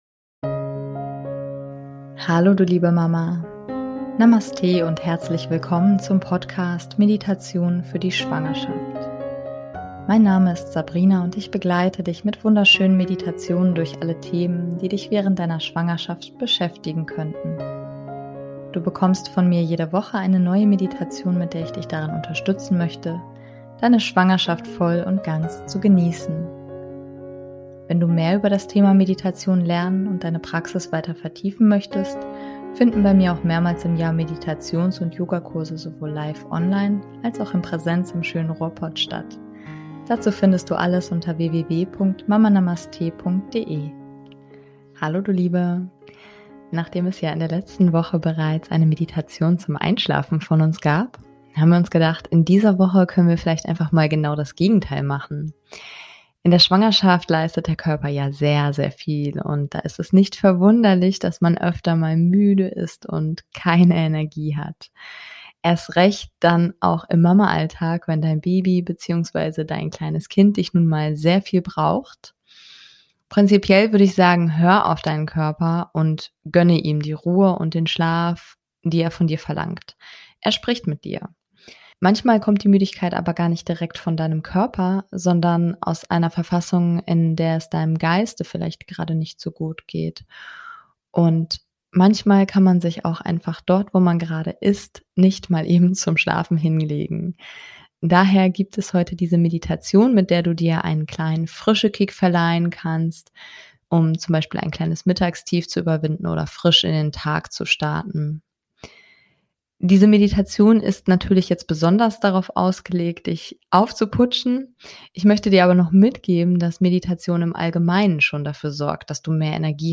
#062 - Energie Booster Meditation [für Schwangere und Mamas] ~ Meditationen für die Schwangerschaft und Geburt - mama.namaste Podcast